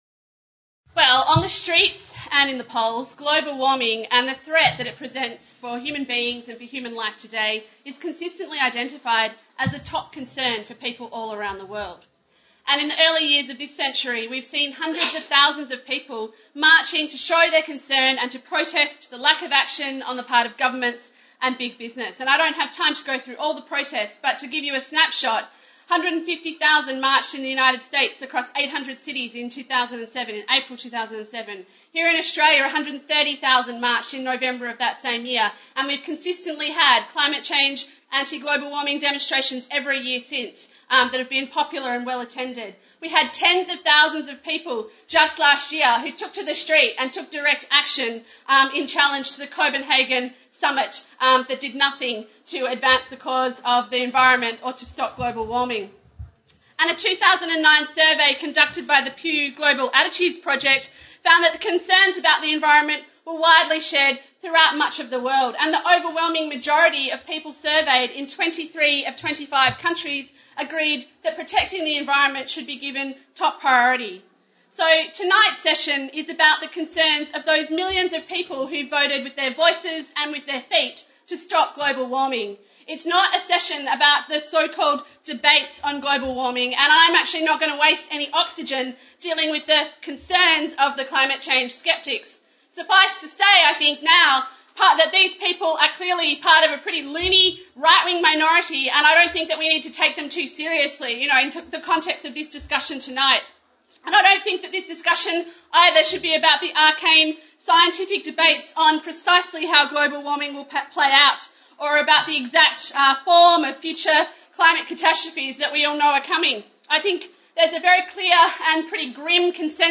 Marxism 2010